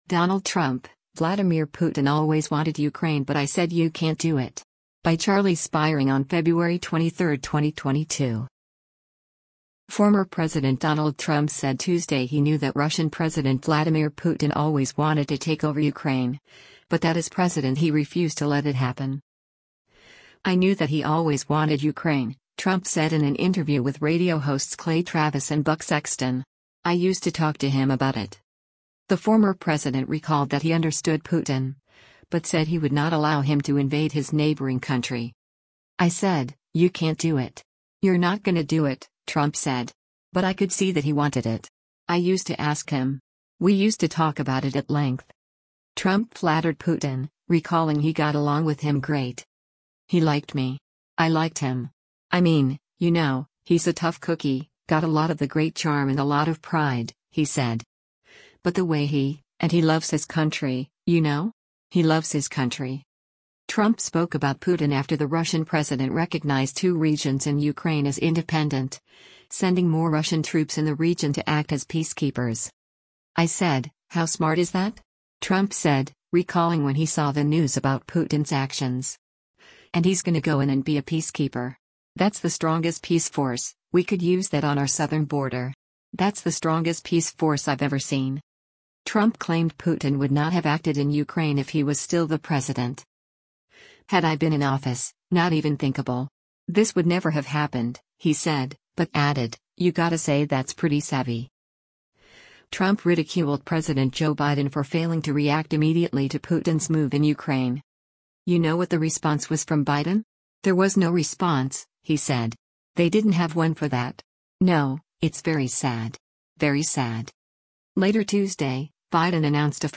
“I knew that he always wanted Ukraine,” Trump said in an interview with radio hosts Clay Travis and Buck Sexton.